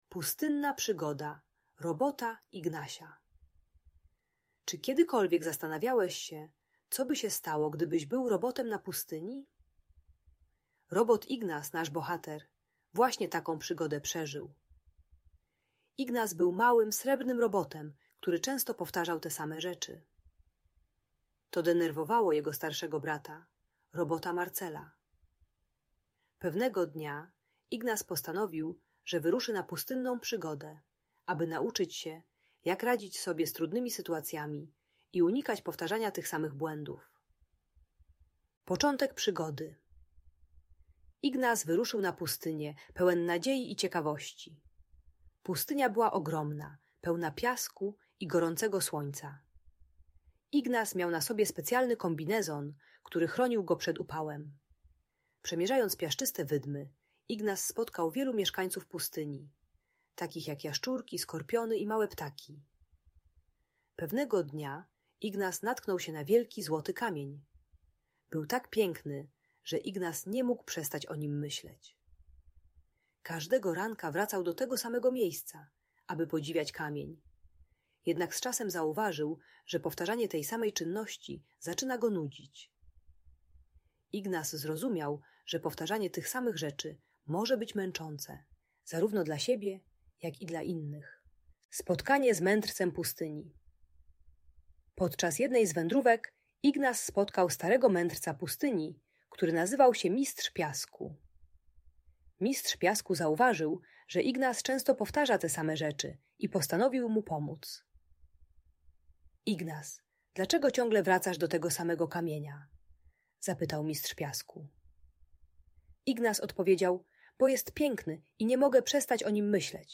Przygoda Robota Ignasia - Rodzeństwo | Audiobajka